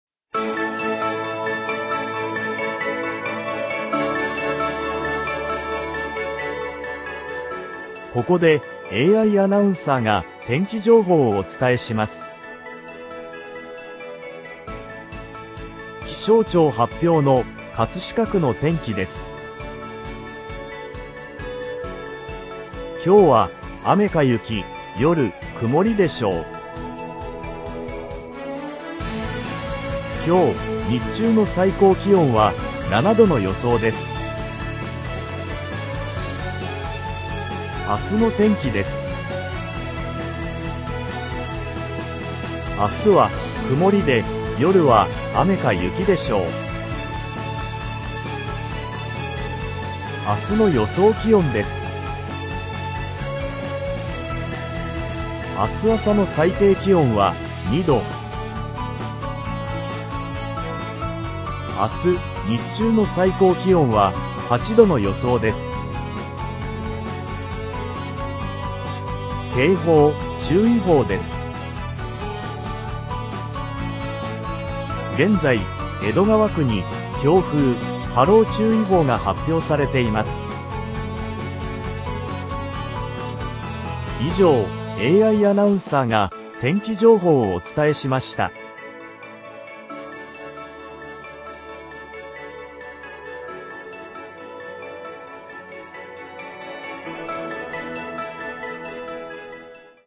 アイダスは、アナウンサーの肉声をもとに合成する高品位の音声合成システム、および、最近の人工知能(AI)を活用した多言語AIアナウンサー・AI天気予報システムを開発・運用しています。